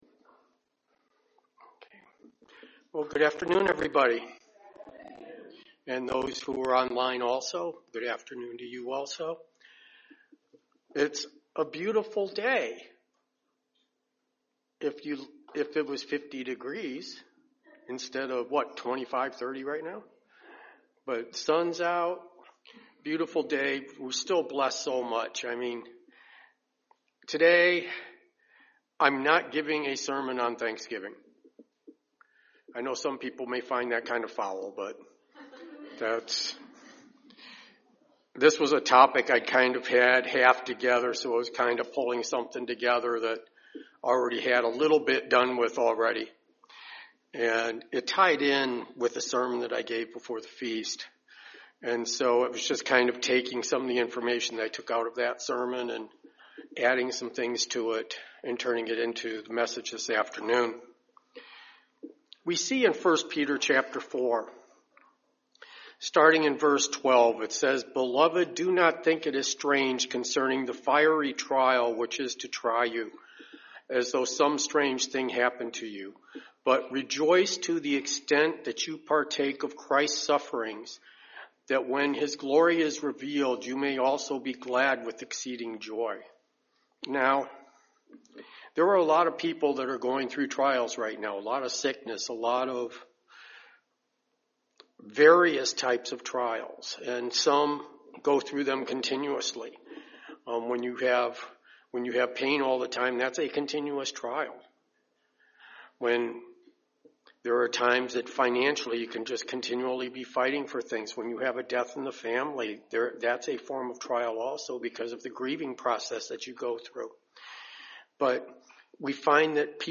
Sermons
Given in Dayton, OH